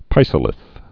(pīsə-lĭth, -zə-, pĭsə-, pĭzə-)